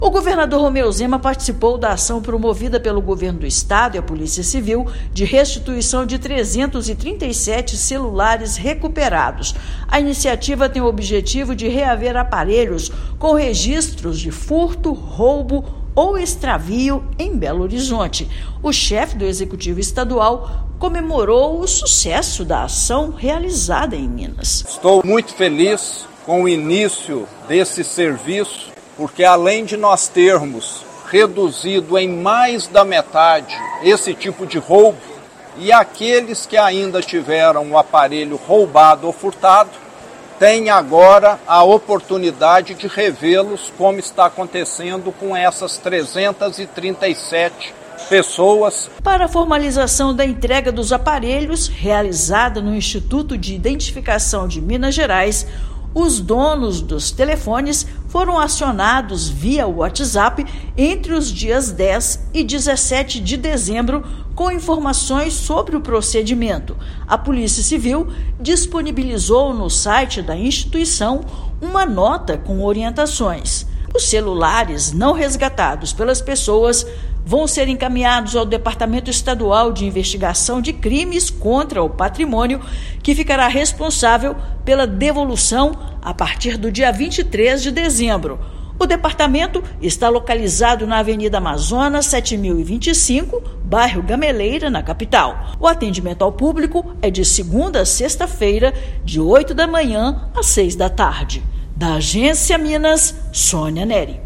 Na iniciativa Tá Entregue, proprietários foram convidados a comparecer ao Instituto de Identificação para a formalização da entrega dos aparelhos. Ouça matéria de rádio.